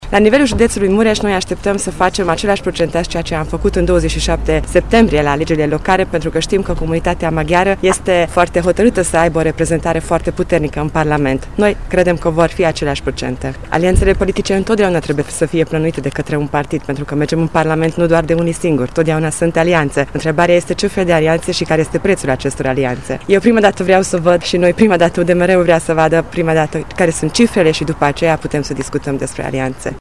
Partidul se așteaptă să obțină un procentaj similar celui de la alegerile locale și nu s-a gândit încă la alianțe politice, a declarat Csep Andrea, actual deputat de Mureș și candidat pentru încă un mandat în parlament: